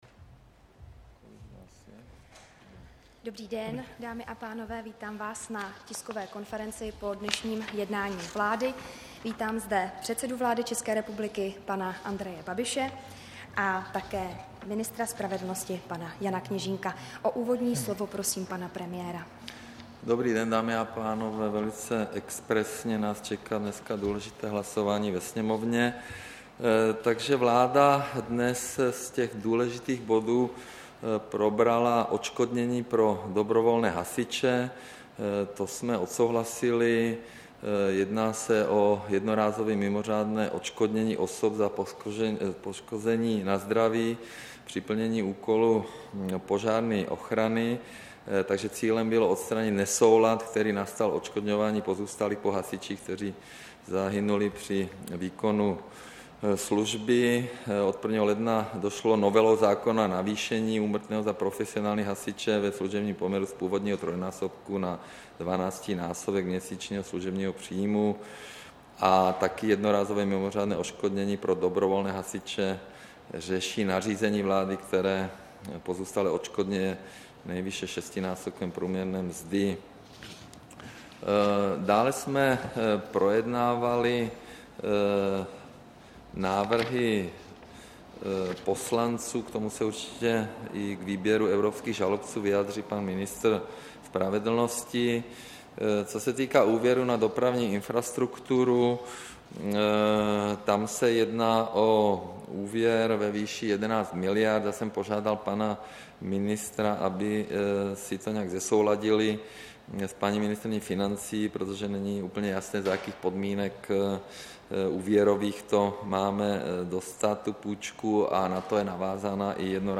Tisková konference po jednání vlády, 31. října 2018